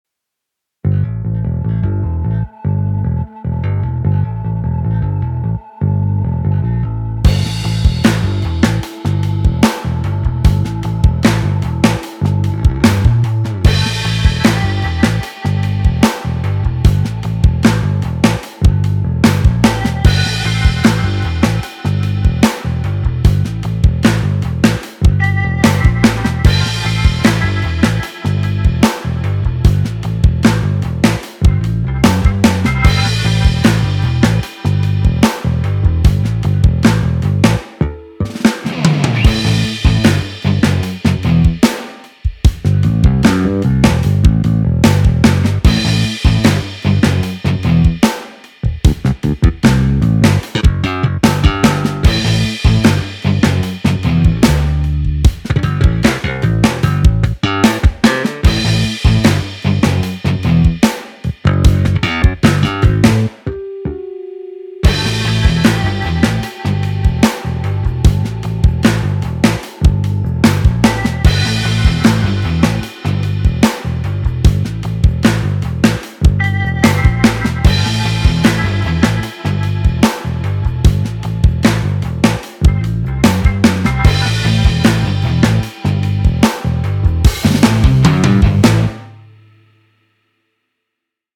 Fat, mix-ready low end
Legendary Fender Precision sound
Cabinet emulation
Psychedelic rock
Solo